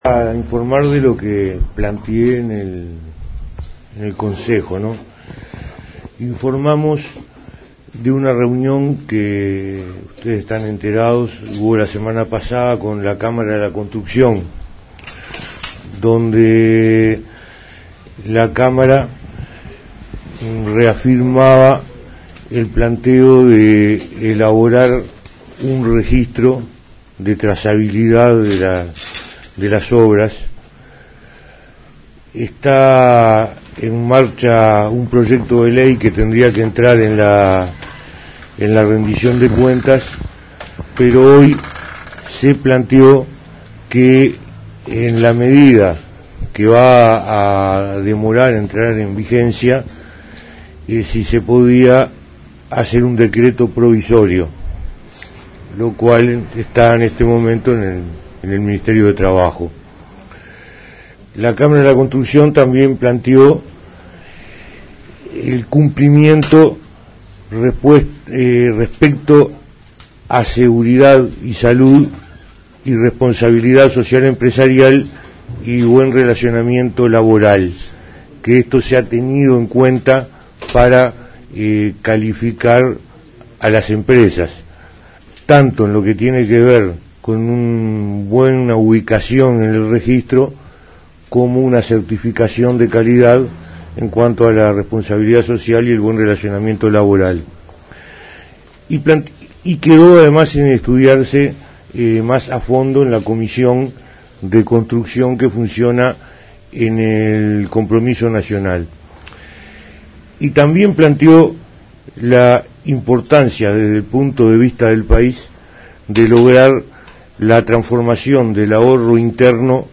Declaraciones del Ministro de Trabajo y Seguridad Social, Eduardo Bonomi, tras los acuerdos mantenidos con el Presidente Vázquez en la Residencia de Suárez.